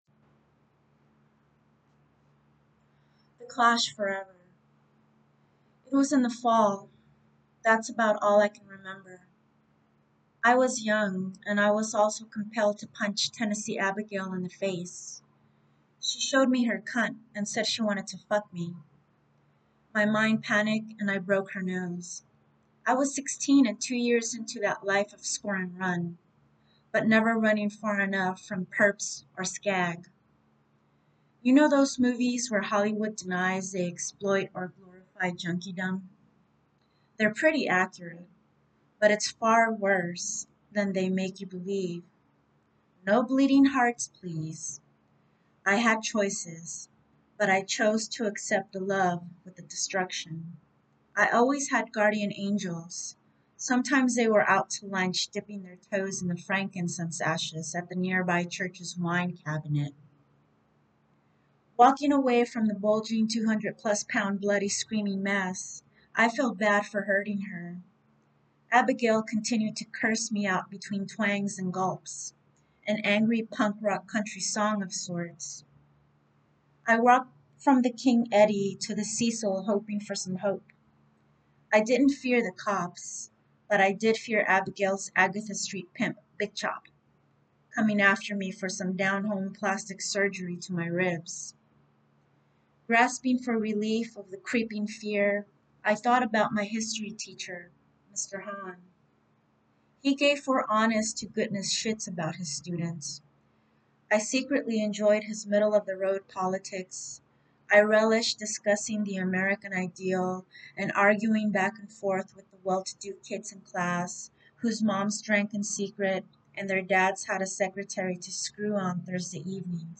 audio reading